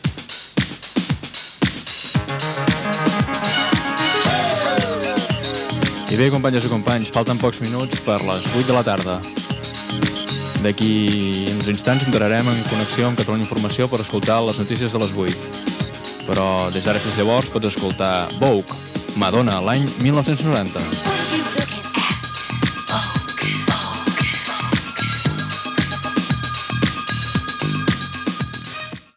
Hora i tema musical
Musical
FM